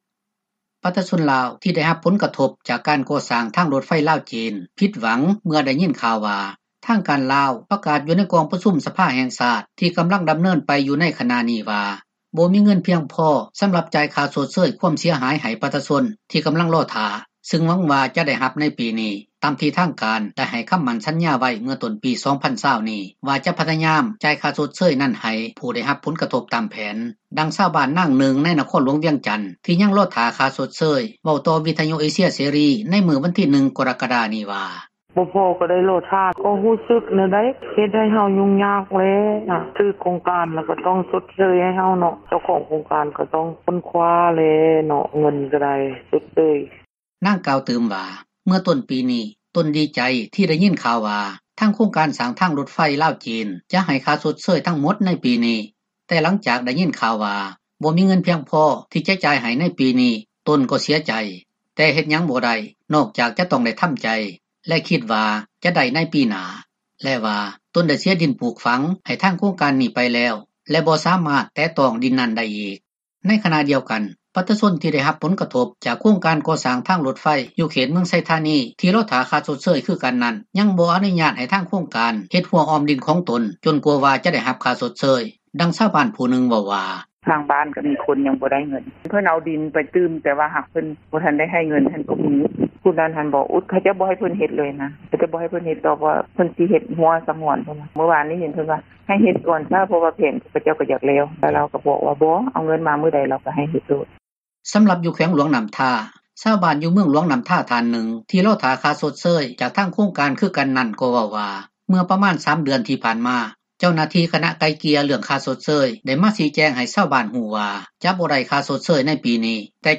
ດັ່ງຊາວບ້ານ ນາງນຶ່ງ ໃນນະຄອນຫຼວງວຽງຈັນ ທີ່ຍັງລໍຖ້າເອົາຄ່າຊົດເຊີຍ ເວົ້າຕໍ່ວິທຍຸເອເຊັຍເສຣີ ໃນມື້ວັນທີ 1 ກໍຣະກະດາ ນີ້ວ່າ: